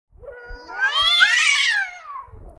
جلوه های صوتی
دانلود صدای جیغ زدن گربه با صدای بلند از ساعد نیوز با لینک مستقیم و کیفیت بالا